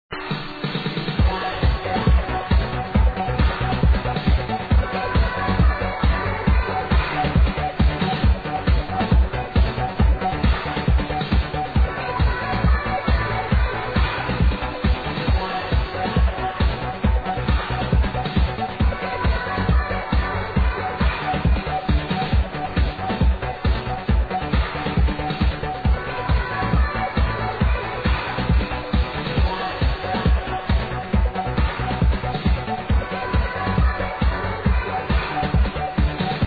Electro Area
in some sort of remix